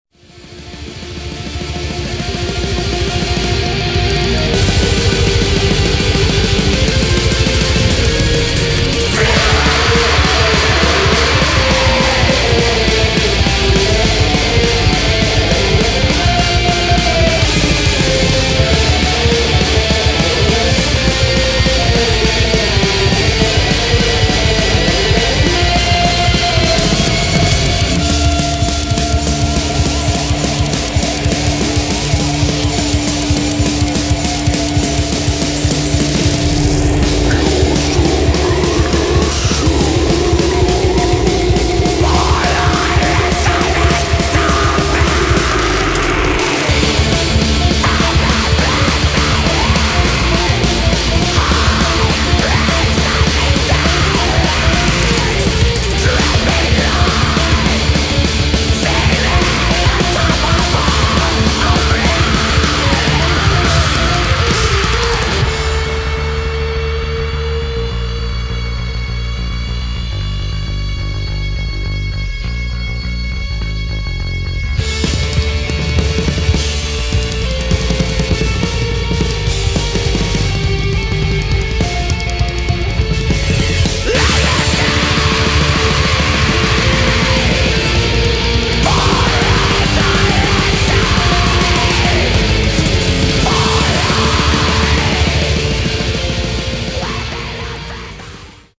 [ HARCORE ]
ブレイクコア/ハードコア/メタル